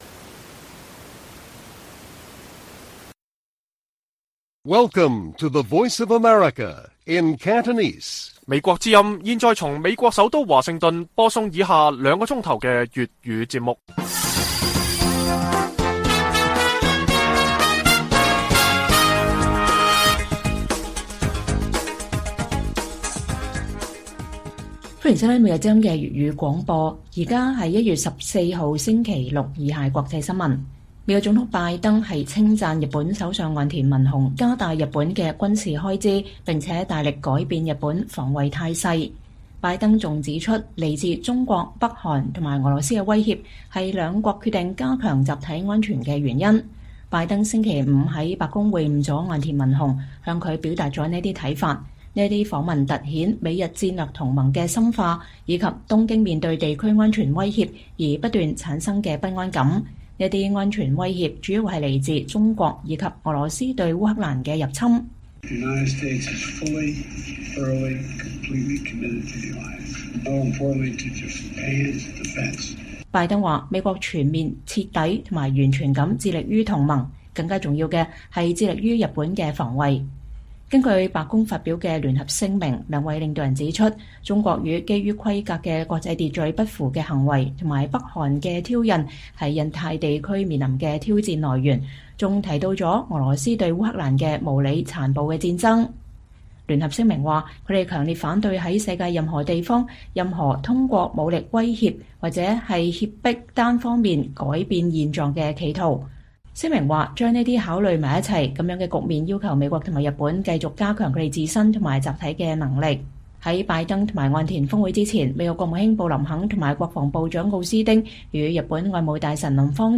粵語新聞 晚上9-10點: 美日首腦白宮峰會，聚焦抗衡中俄